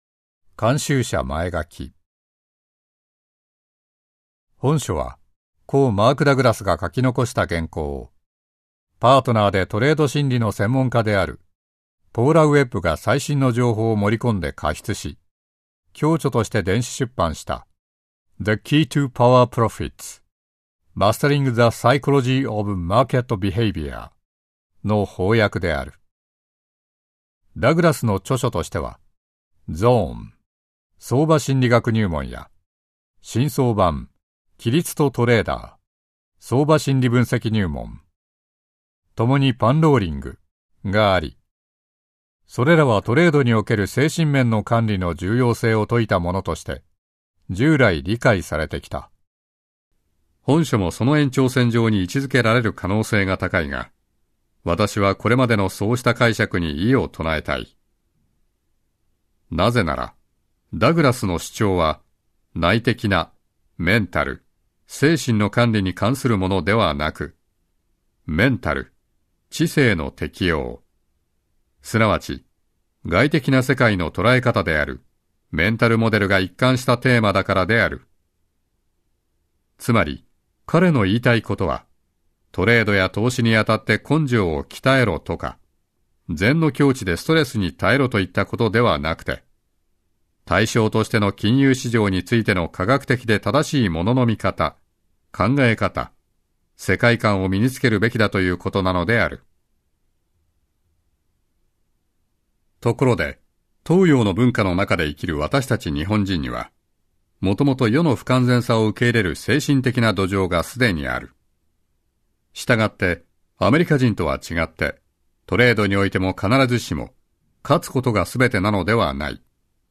[オーディオブック] 新装版 ゾーン 最終章